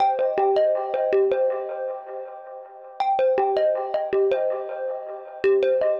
Ambient / Keys / SYNTH014_AMBNT_160_C_SC3(L).wav
1 channel